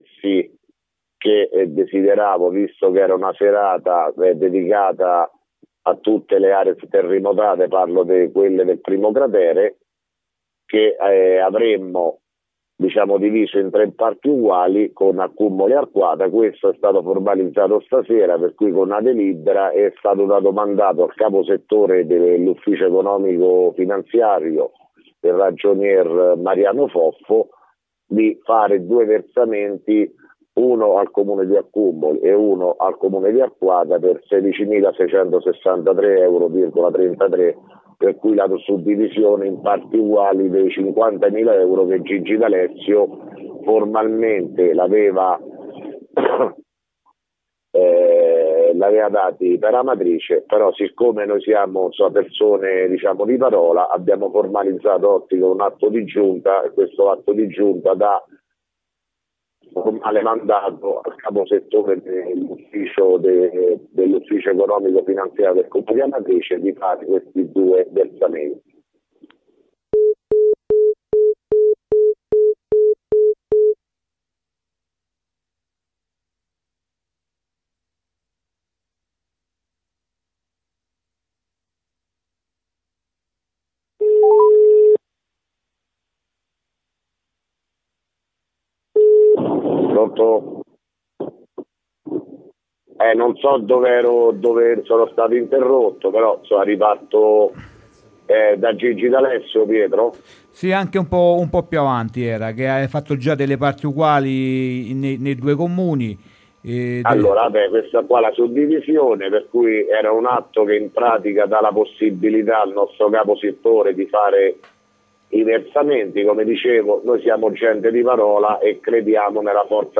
Di seguito il messaggio audio del Sindaco Sergio Pirozzi del 23 marzo 2017